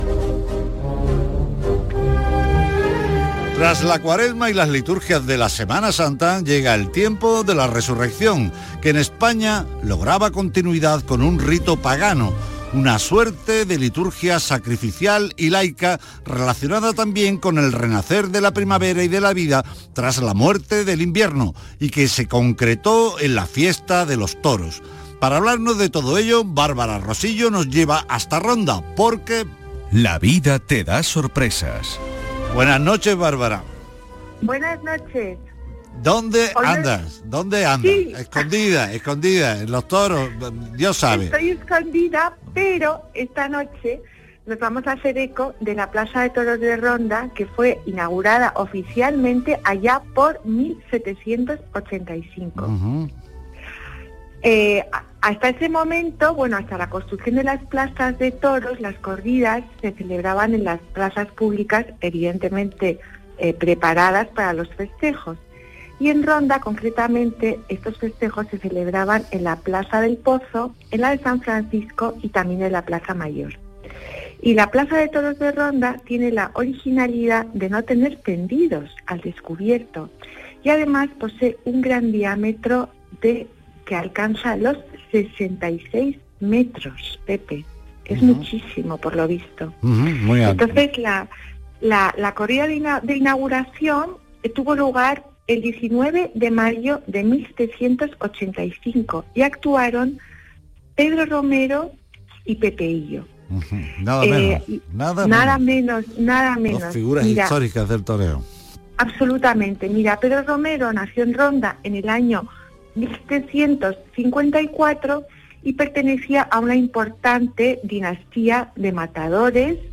Aquí os dejo mi intervención en el programa de Radio Andalucía Información, «Patrimonio andaluz» del día 17/04/2022.